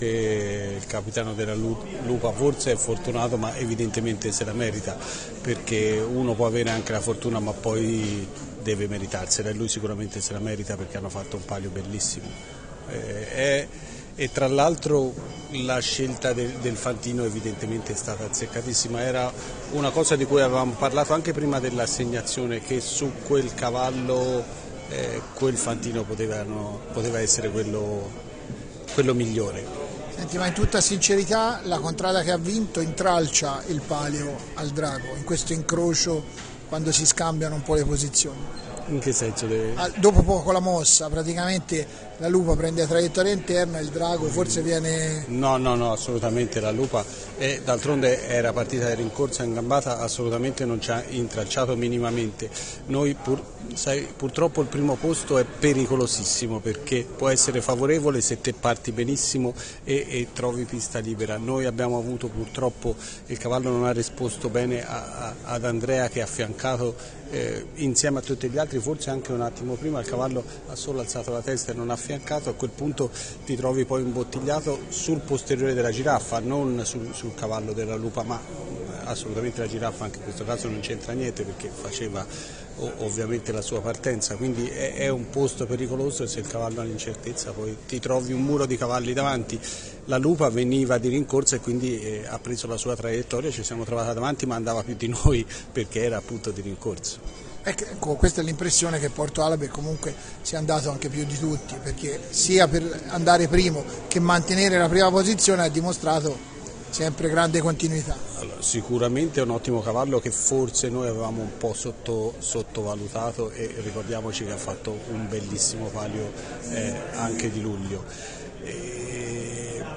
Interviste
Come di consueto, dopo la carriera, abbiamo raccolto i commenti dei capitani delle contrade che hanno partecipato al palio del 16 agosto.